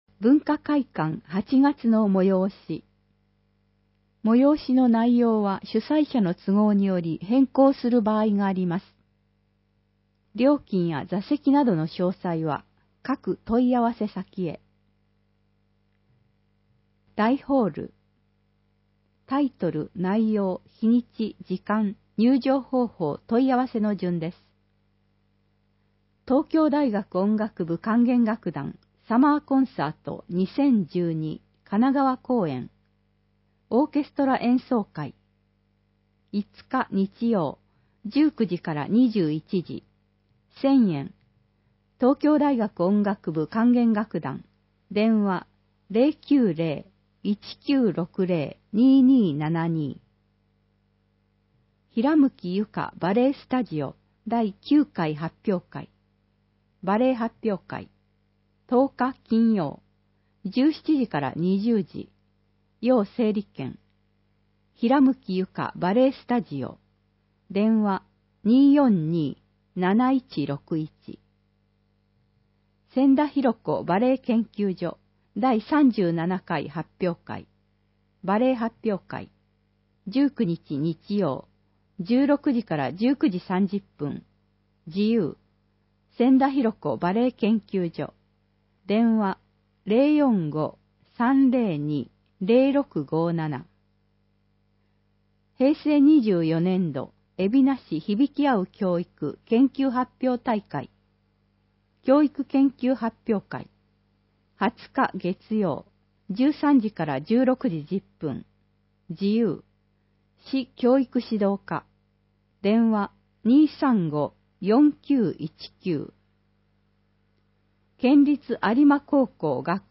※音声版は、音声訳ボランティア「矢ぐるまの会」の協力により、同会が視覚障がい者の方のために作成したものを、順次搭載します。